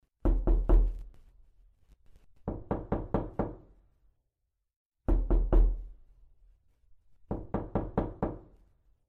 8d Knocking Sound